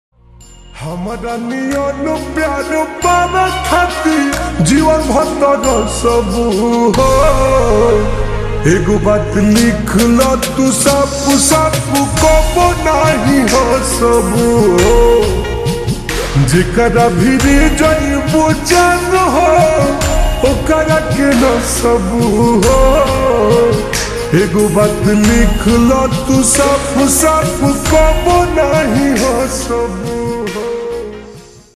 Bhojpuri Song
(Slowed + Reverb)